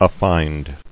[af·fined]